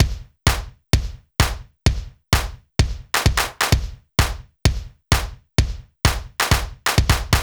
BAL Beat - Mix 4.wav